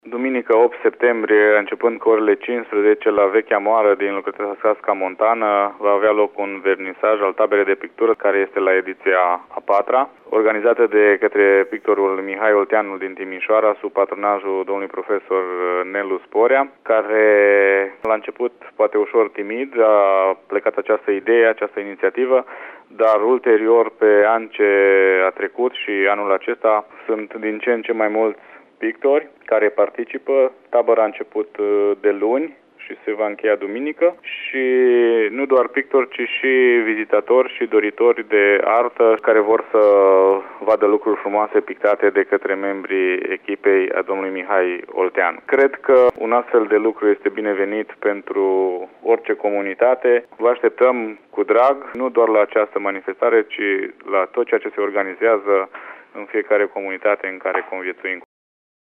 Ion Poplicean, primarul comunei Sasca Montană